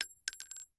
5caee9fba5 Divergent / mods / Bullet Shell Sounds / gamedata / sounds / bullet_shells / rifle_generic_5.ogg 18 KiB (Stored with Git LFS) Raw History
rifle_generic_5.ogg